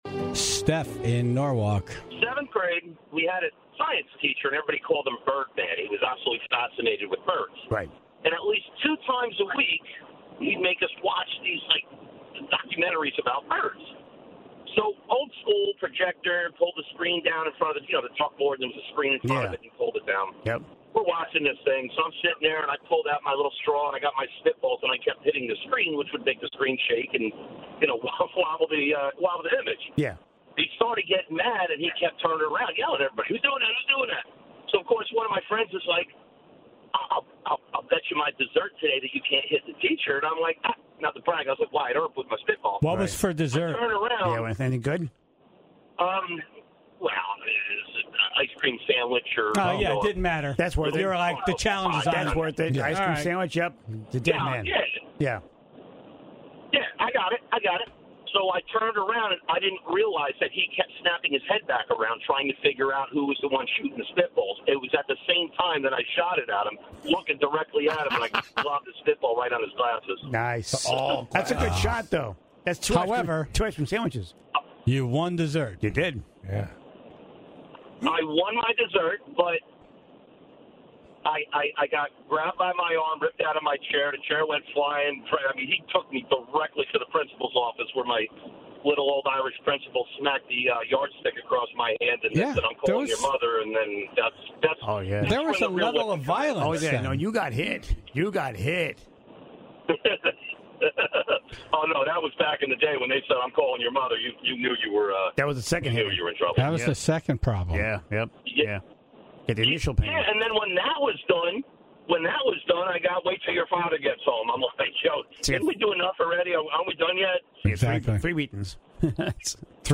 Selling joints, shooting spitballs, ditching class and one caller who threw a knife into the wall at the back of the classroom.